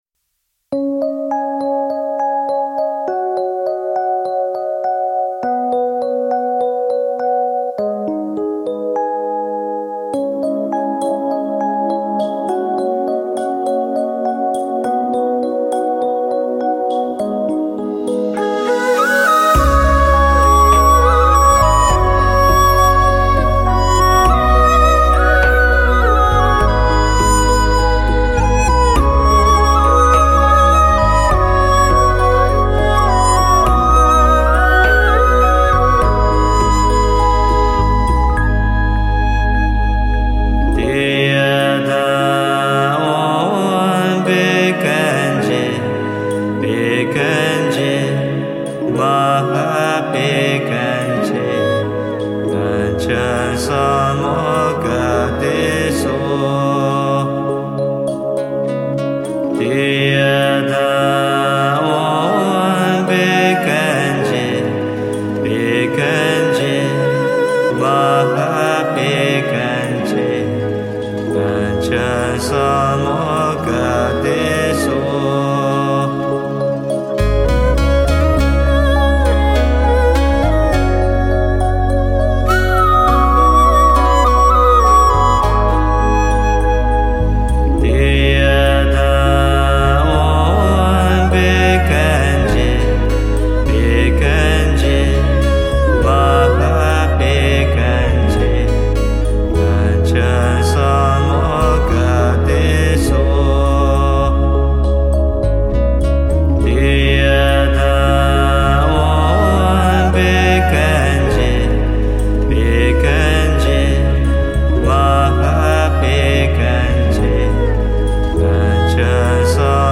配合法器、国乐及电子乐等演奏形式, 让听者进入非常完美的自然状态，